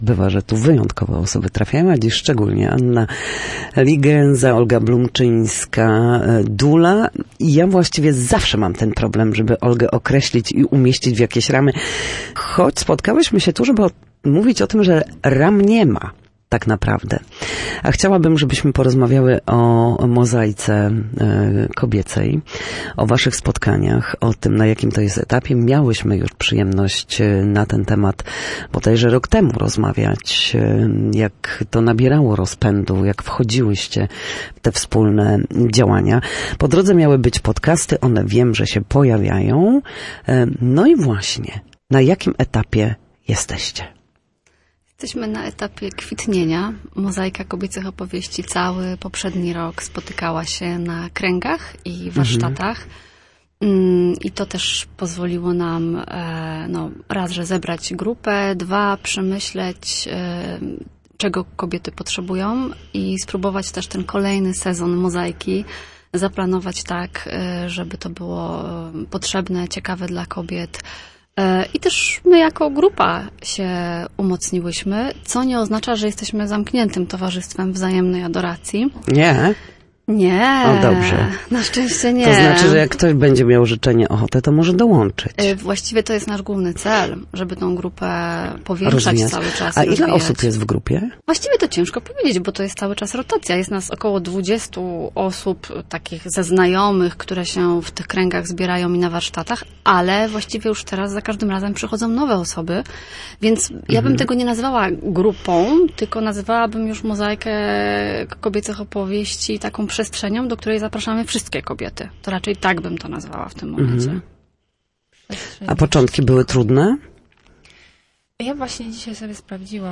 W każdą środę w popołudniowym Studiu Słupsk Radia Gdańsk dyskutujemy o tym, jak wrócić do formy po chorobach i urazach.